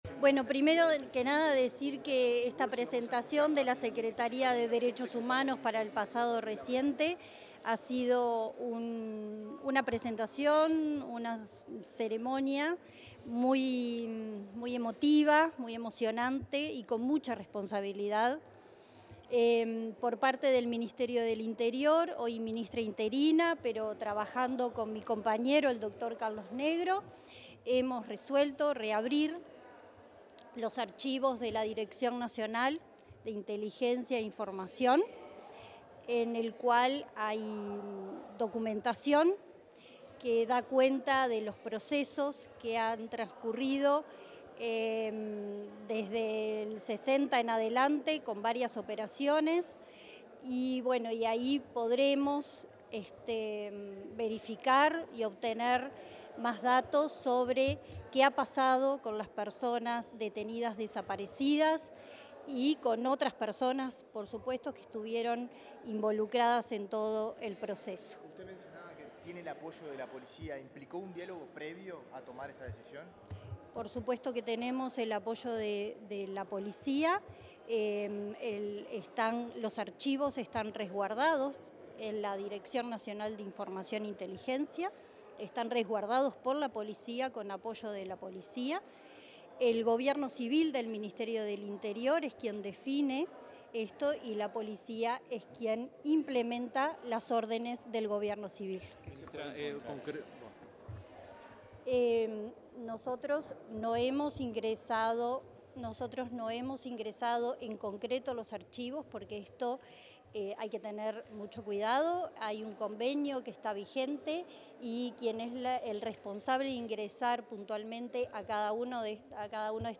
Declaraciones a la prensa de la ministra interina del Interior, Gabriela Valverde
La ministra interina del Interior, Gabriela Valverde, dialogó con la prensa tras la presentación de los avances y lineamientos previstos para el